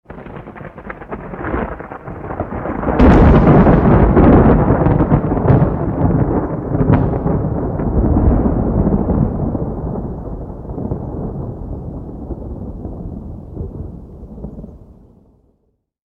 thunder_large_crack_se.wav